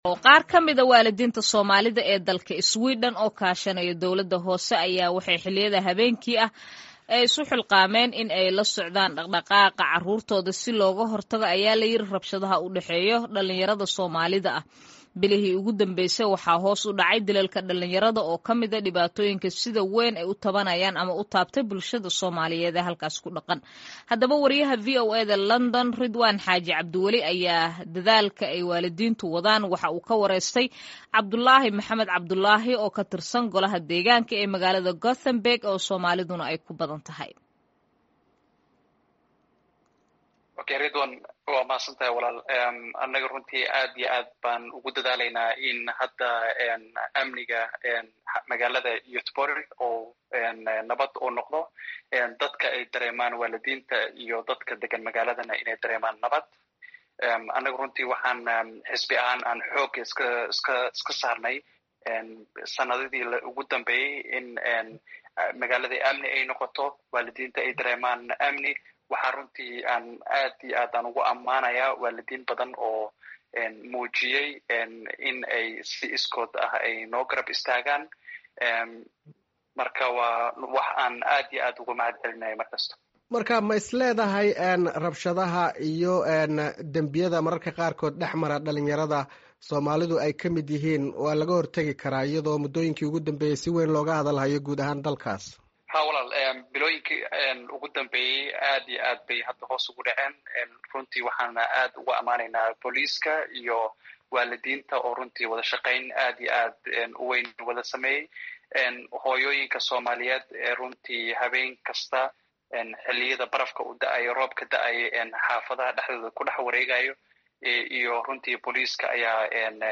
Wareysi: Soomaalida Sweden oo bilaabay olole lagu badbaadinayo carruurta